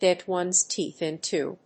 gèt one's téeth ìnto…